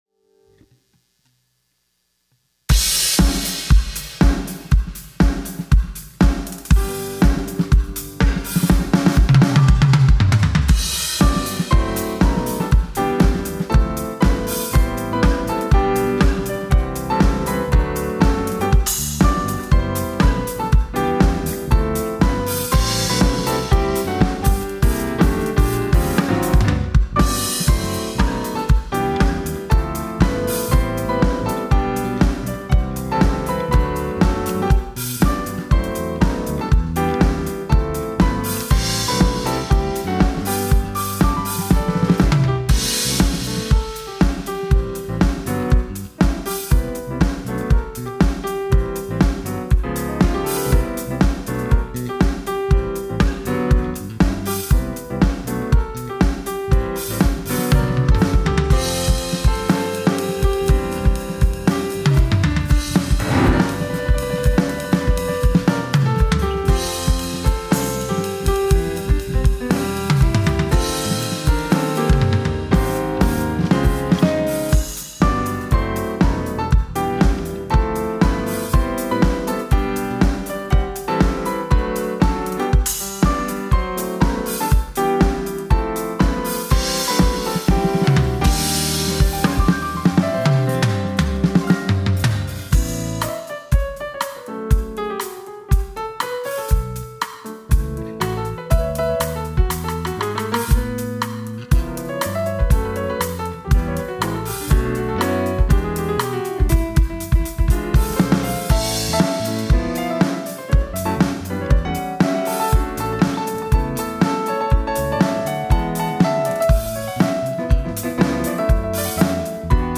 특송과 특주 - 나의 슬픔을
청년부